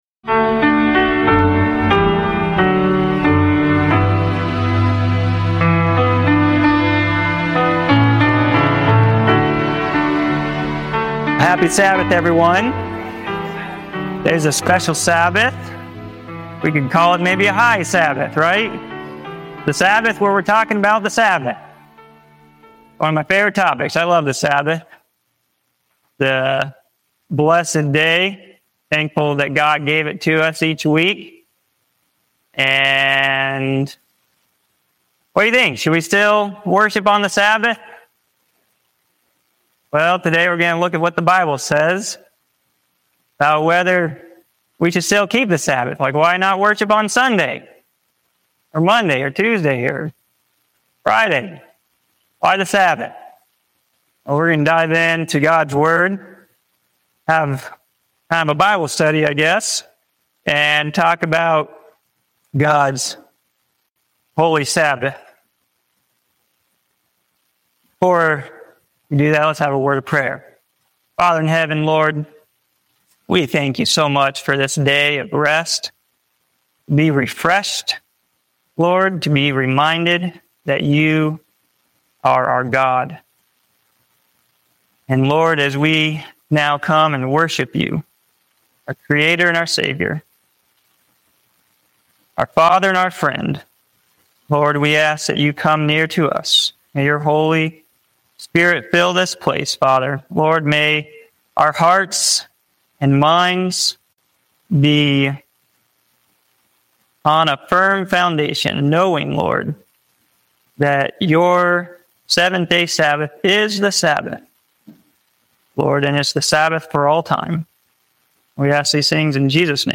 This sermon uncovers the timeless truth of the Sabbath, tracing its origin from creation to eternity and showing its continued relevance for all believers. With biblical evidence, Jesus’ example, and even health benefits, it highlights the Sabbath as a sign of loyalty, rest, and lasting relationship with God.